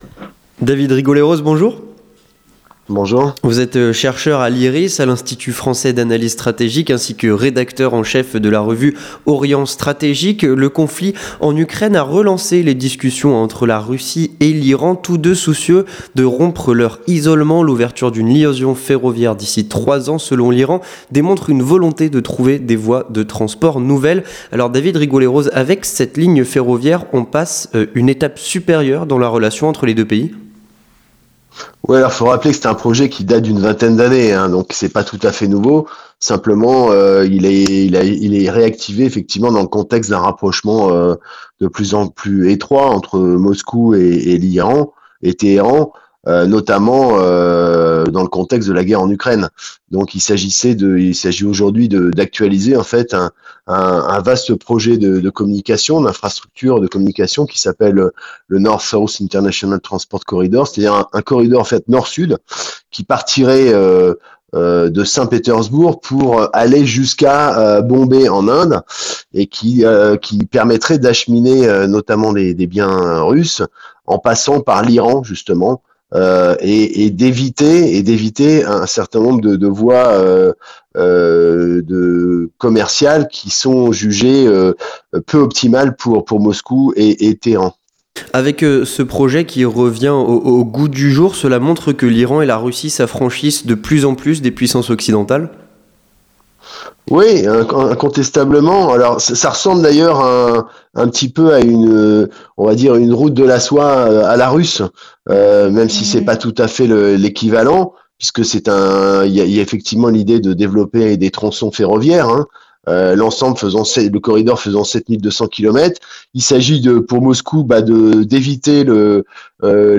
Entretien du 18h - Le rapprochement entre l'Iran et la Russie, un danger pour Israël ?